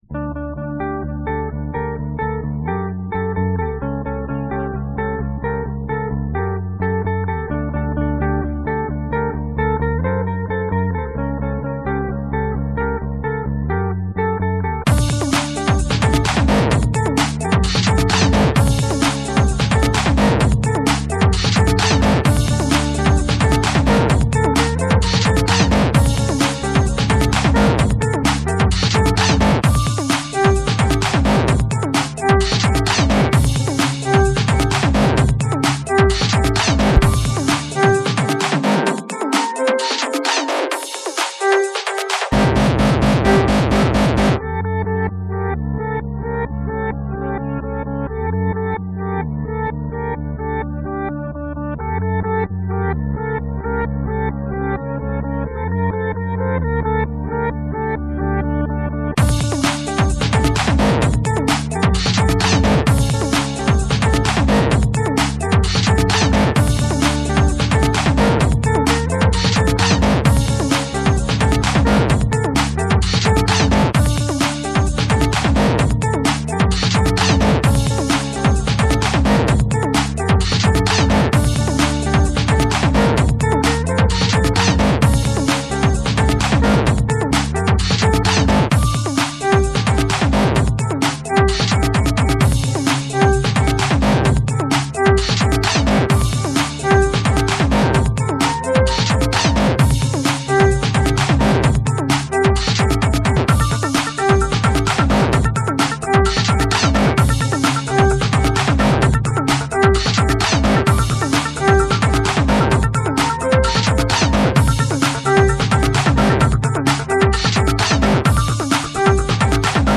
dance/electronic
Deep breakbeat house.
Breaks & beats
IDM